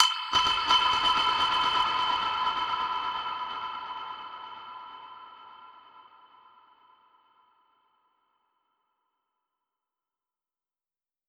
Index of /musicradar/dub-percussion-samples/85bpm
DPFX_PercHit_D_85-06.wav